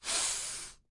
描述：沉重的气息
Tag: 吸气 喘气 呼吸